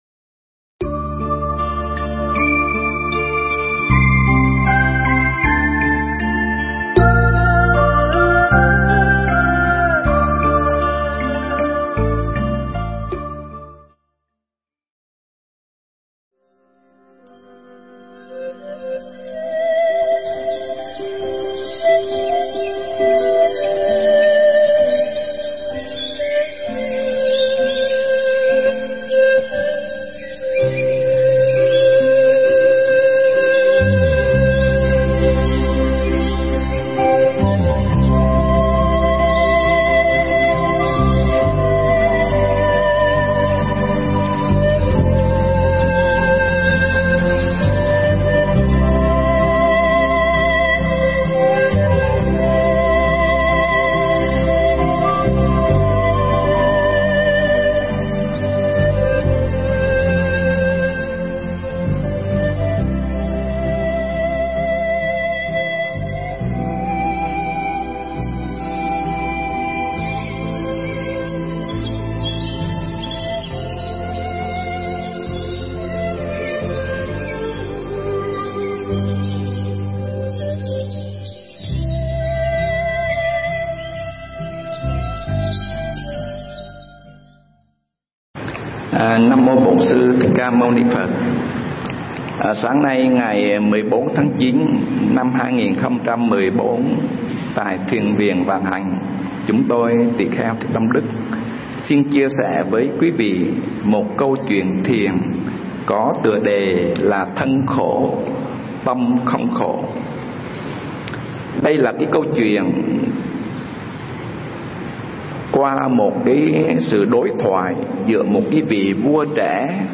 Nghe Mp3 thuyết pháp Thân Khổ